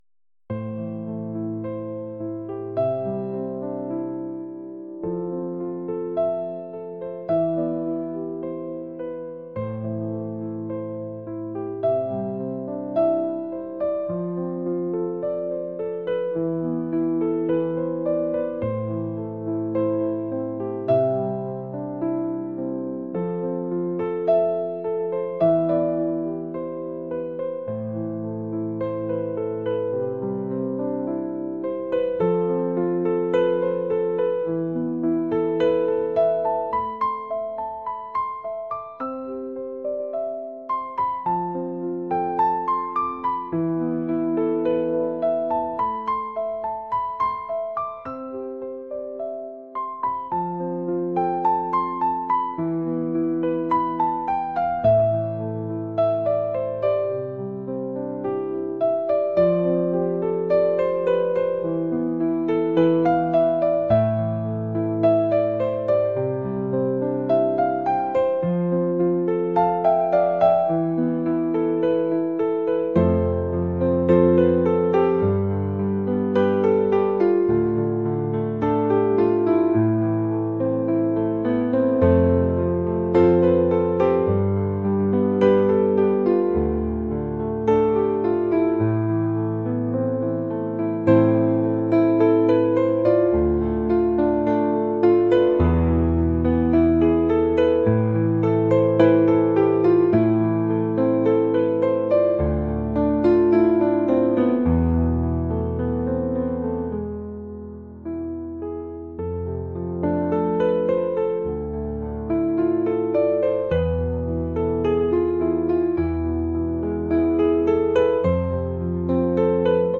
ambient | pop | cinematic